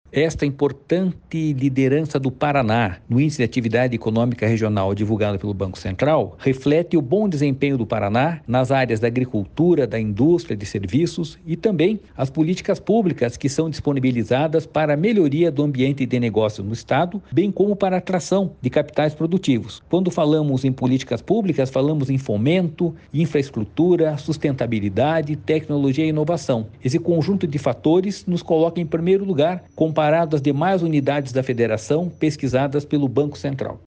Sonora do diretor-presidente do Ipardes, Jorge Callado, sobre o Paraná ter o maior crescimento da atividade econômica do Brasil em 2023